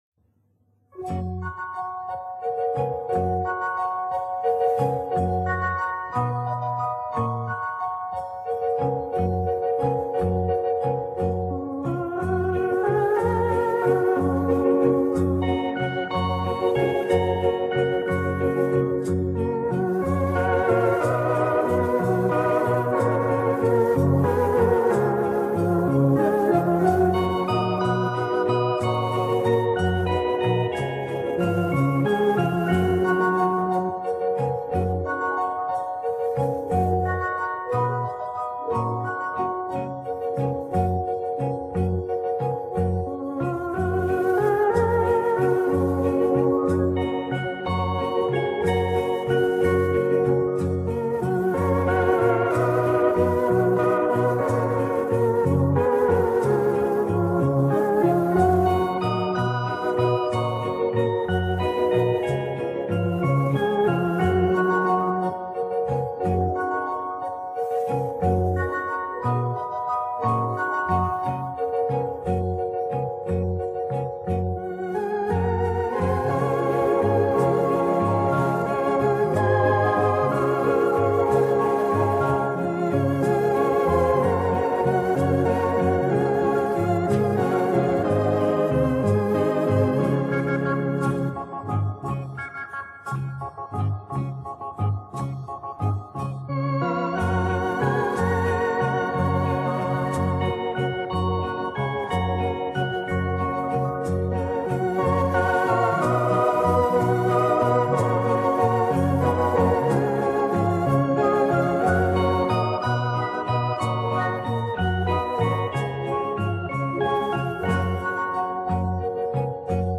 Genre:Jazz, Pop
Style:Easy Listening